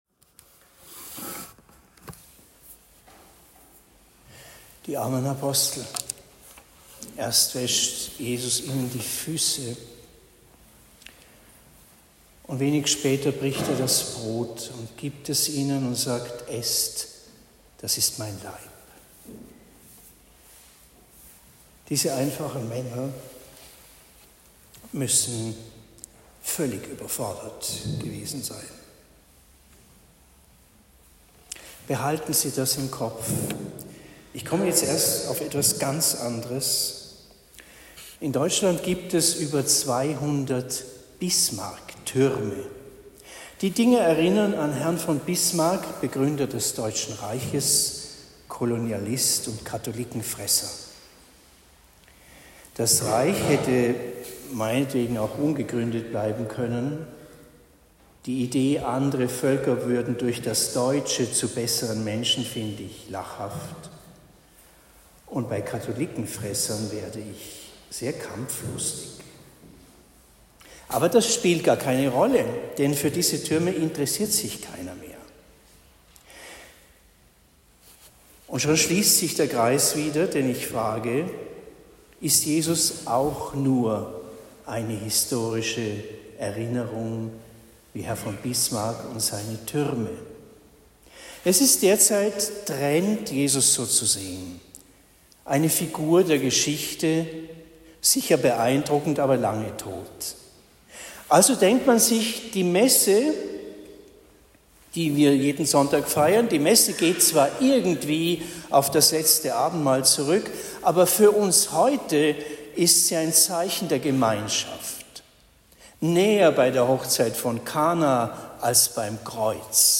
Predigt in Rettersheim St.-Ulrich am 28. März 2024